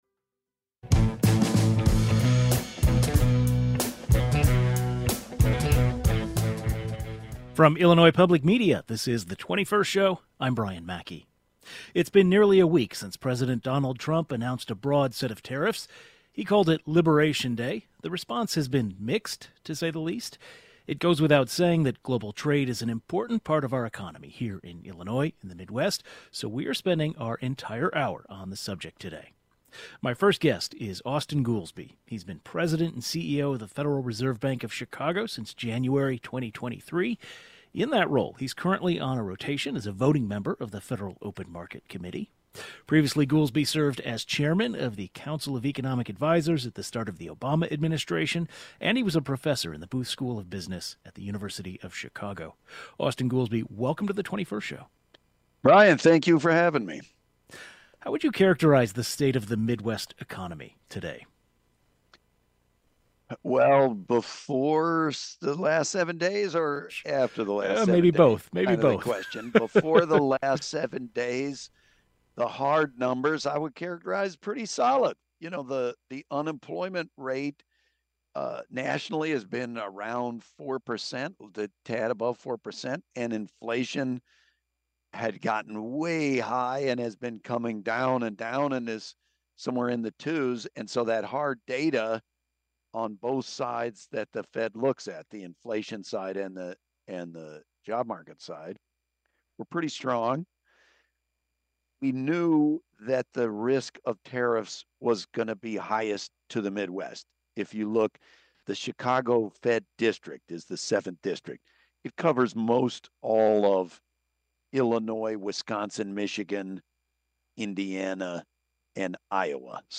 GUEST Austan Goolsbee President and CEO, Federal Reserve Bank of Chicago (2023-present) Chairman, Council of Economic Advisers (Obama, 2009-2011) Robert P. Gwinn Professor, University of Chicago Booth School of Business